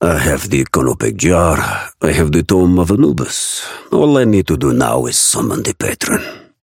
Raven voice line - I have the Canopic Jar, I have the Tome of Anubis.